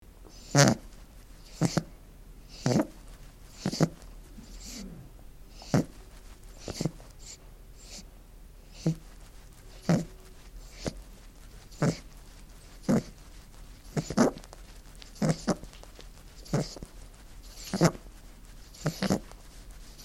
Bunny Lick Feet Bouton sonore
Animal Sounds Soundboard229 views